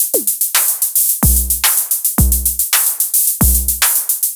Dirty Club Break 110.wav